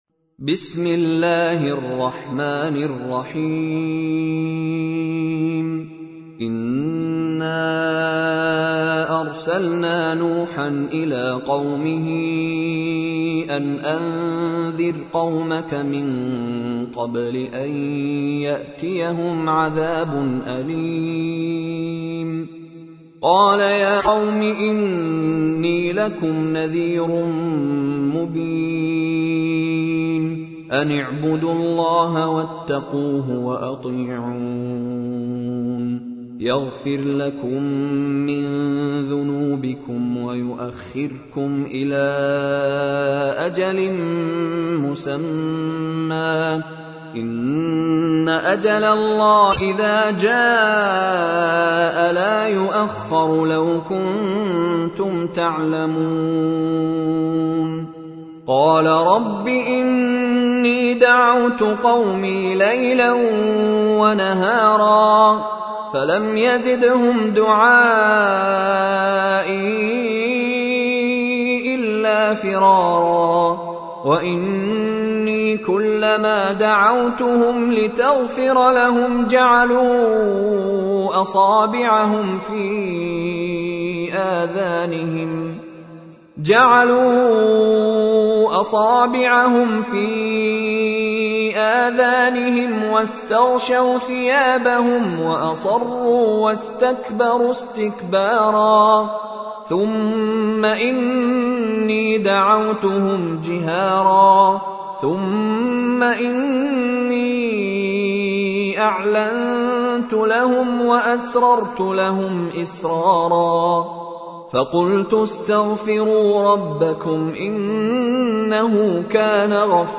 تحميل : 71. سورة نوح / القارئ مشاري راشد العفاسي / القرآن الكريم / موقع يا حسين